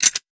weapon_foley_pickup_23.wav